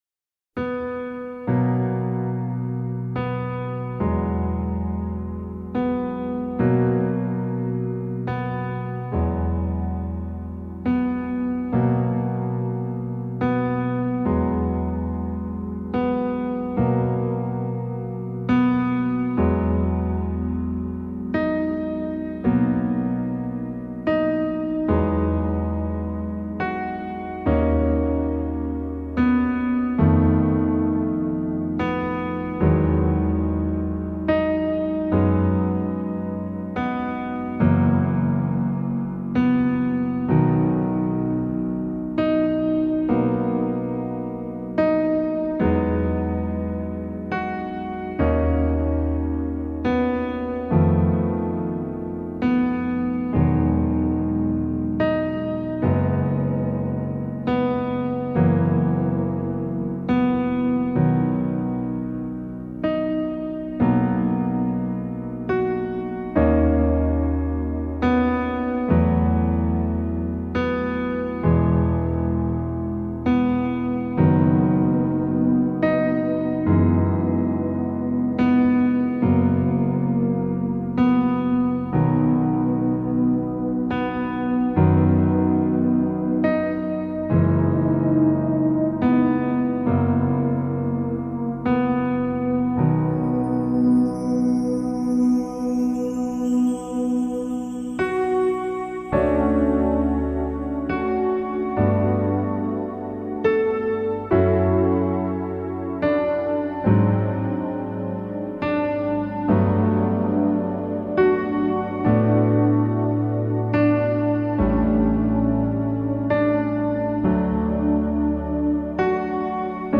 南の島のアジアン·リゾート·サウンド
南方岛国的亚洲渡假音乐